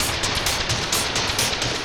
RI_DelayStack_130-01.wav